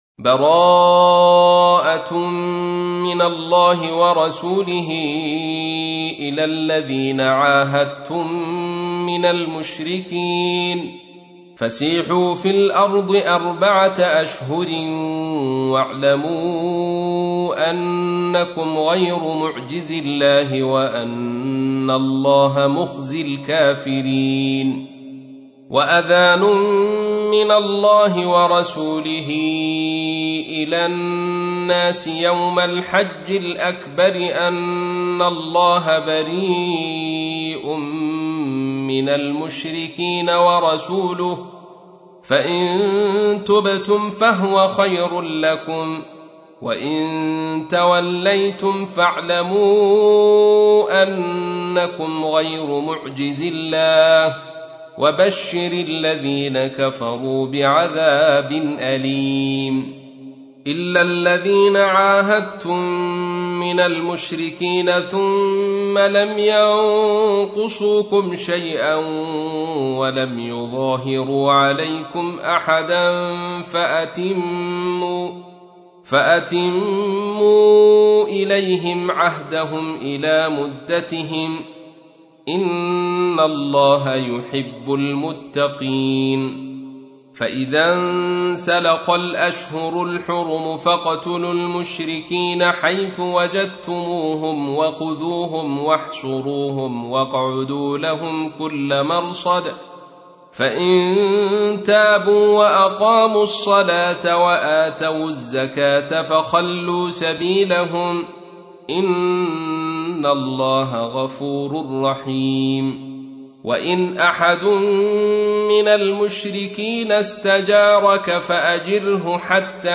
تحميل : 9. سورة التوبة / القارئ عبد الرشيد صوفي / القرآن الكريم / موقع يا حسين